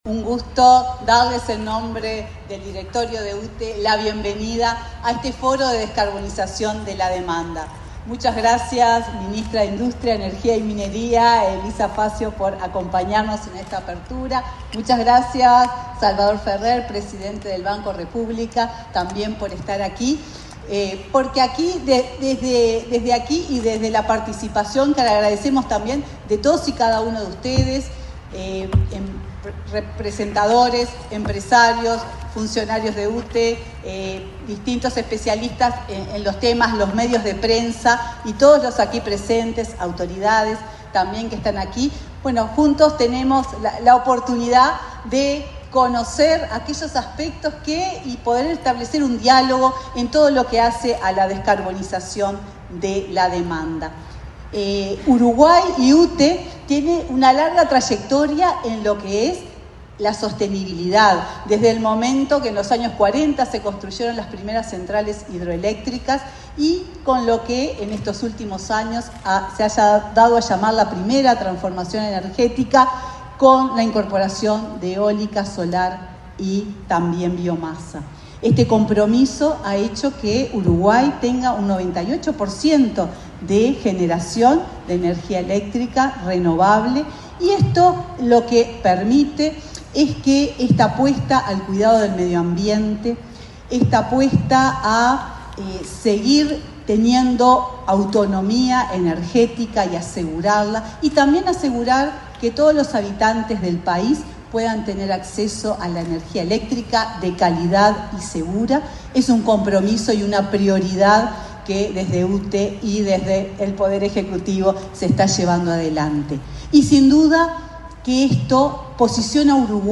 Palabras de autoridades en foro sobre transformación energética
Palabras de autoridades en foro sobre transformación energética 16/08/2024 Compartir Facebook X Copiar enlace WhatsApp LinkedIn Este viernes 16 en el Laboratorio Tecnológico del Uruguay, la presidenta de la UTE, Silvia Emaldi; el titular del Banco de la República, Salvador Ferrer, y la ministra de Industria, Elisa Facio, participaron en la apertura de un foro sobre transformación energética, organizado por la UTE.